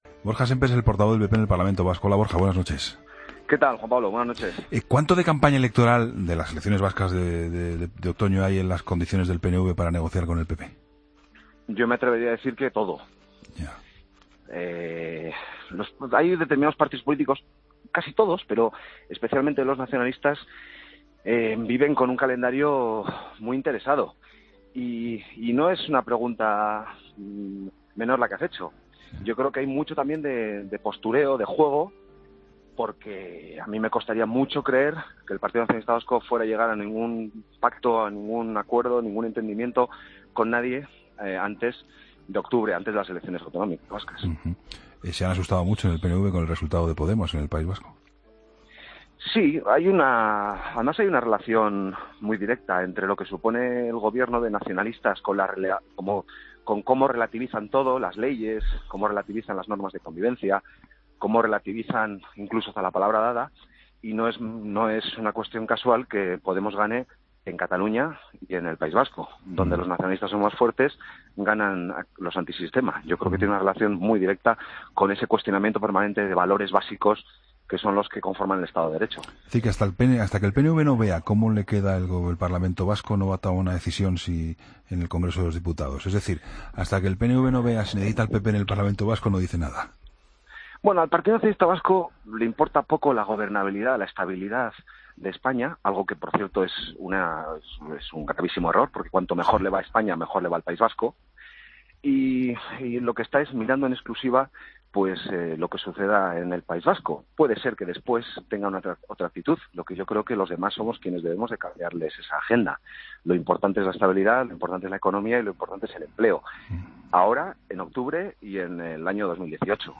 Entrevista al portavoz del PP en el Parlamento Vasco, Borja Semper, en 'La Linterna'